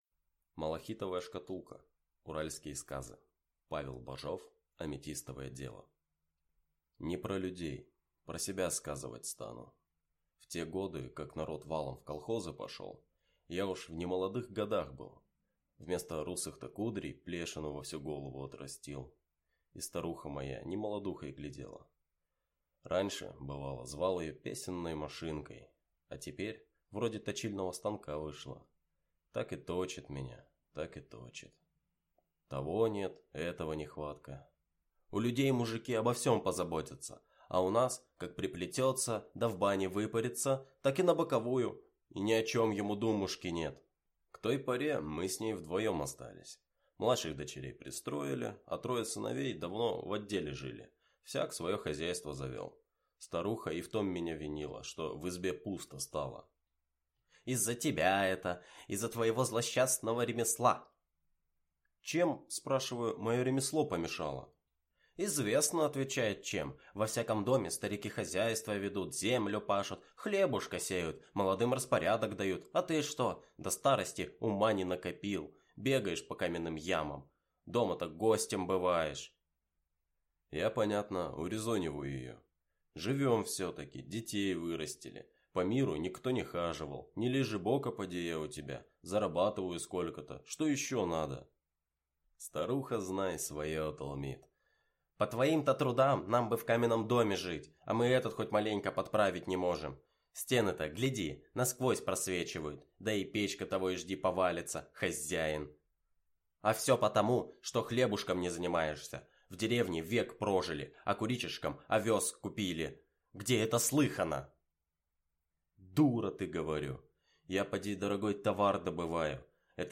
Аудиокнига Аметистовое дело | Библиотека аудиокниг